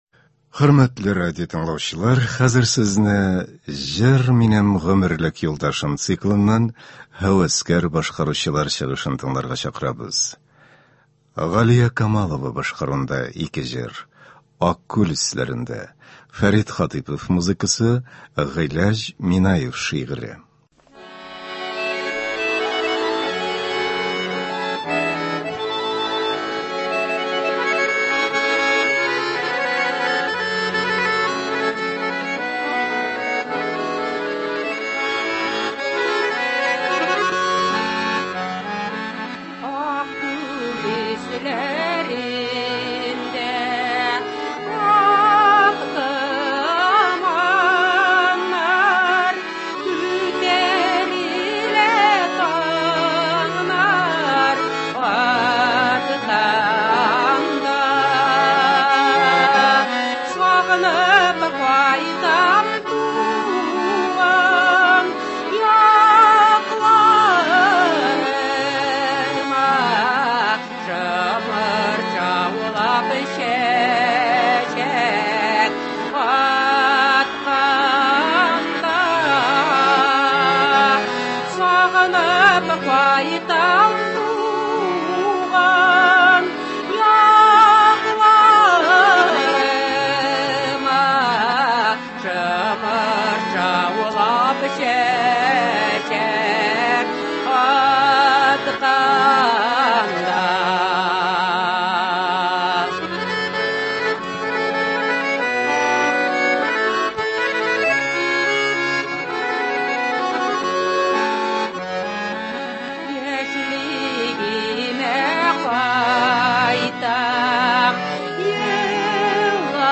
Бу кичне дә таныш моңнар белән уздырабыз.